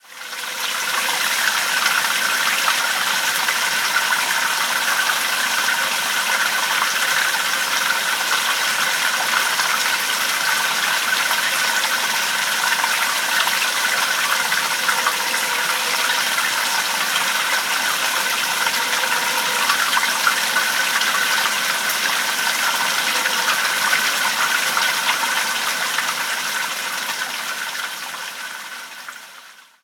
Fuente abrevadero
agua
Sonidos: Agua
Sonidos: Rural